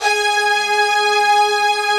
Index of /90_sSampleCDs/Optical Media International - Sonic Images Library/SI1_Fast Strings/SI1_Fast octave